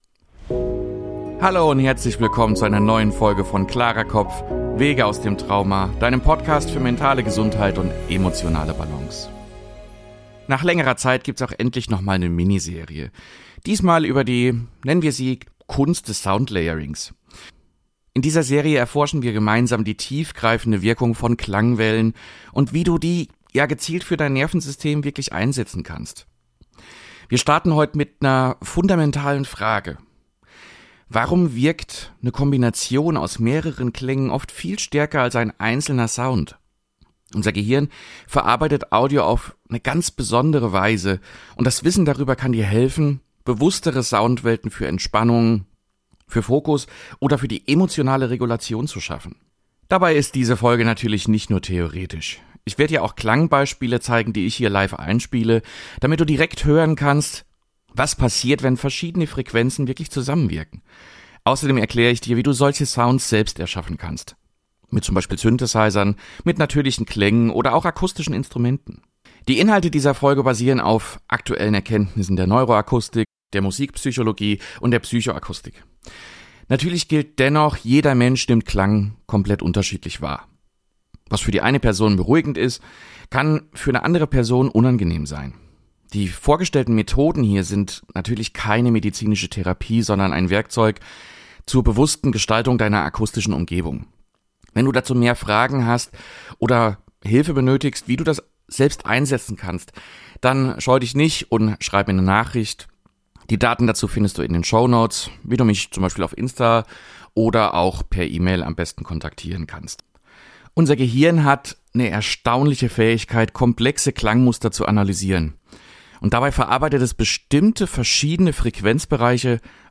🔹 Praktische Soundbeispiele – Ich zeige dir live, wie man Klangschichten baut und welche Kombinationen sich besonders gut für Fokus, Entspannung oder Meditation eignen.